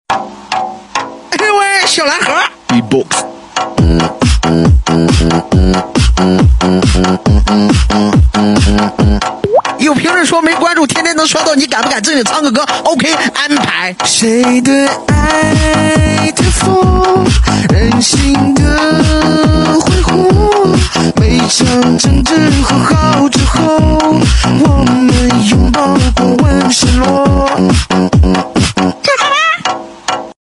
beatbox faucet x Cristiano Ronaldo